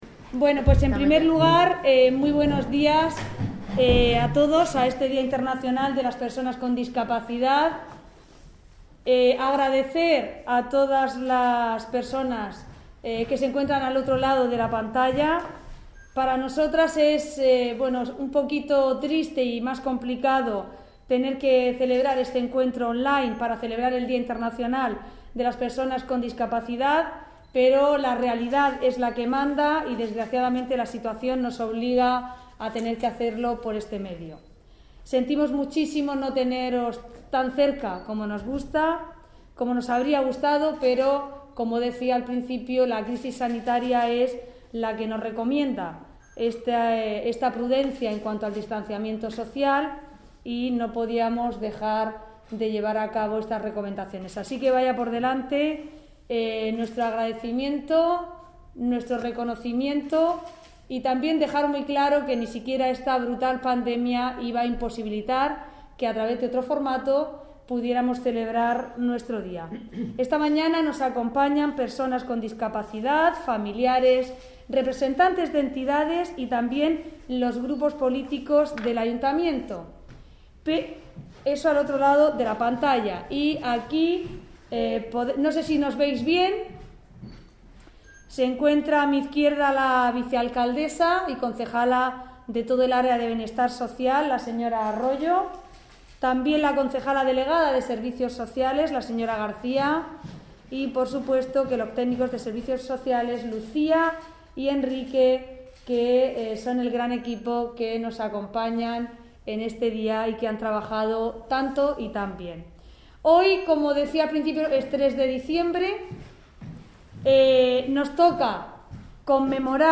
Audio: Declaraciones de Ana Bel�n Castej�n por el D�a Internacional de las Personas con Discapacidad (MP3 - 12,36 MB)